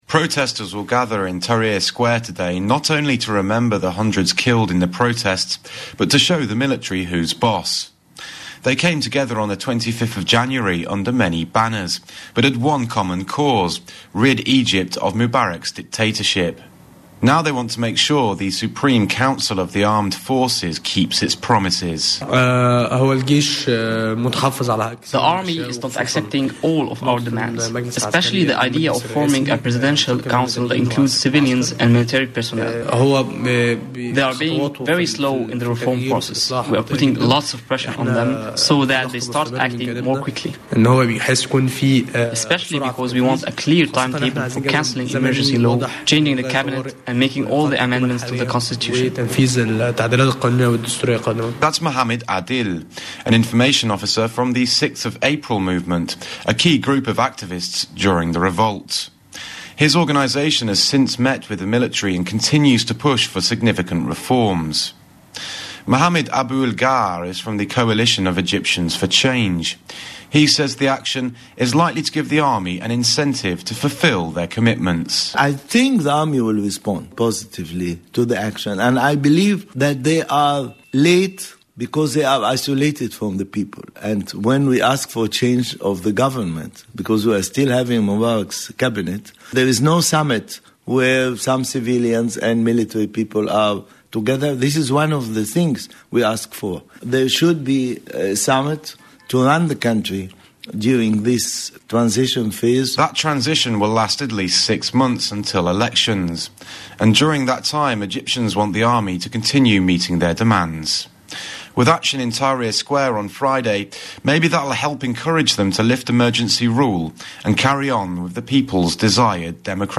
Radio Report: Tahrir Square preview